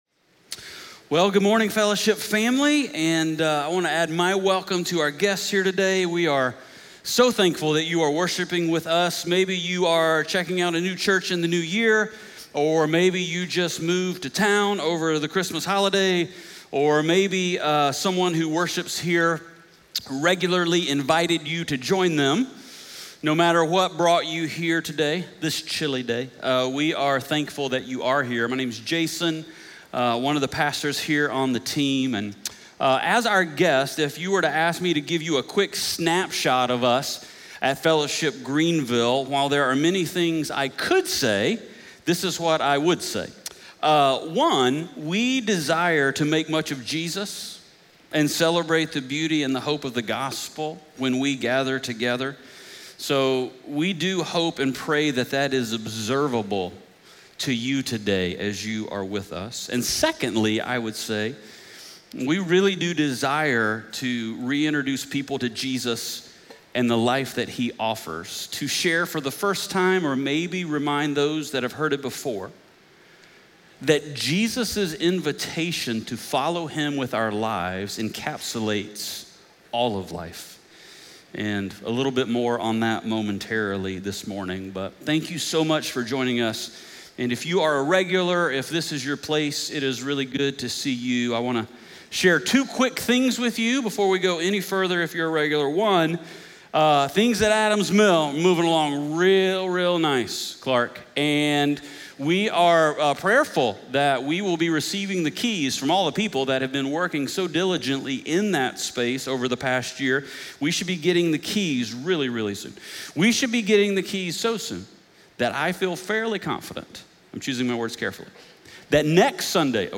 1 Corinthians 16:1-4 Audio Sermon Notes (PDF) Ask a Question Scripture: 1 Corinthians 16:1-4 SERMON SUMMARY This week, we continue our short series on cultivating a gospel-motivated lifestyle of generosity.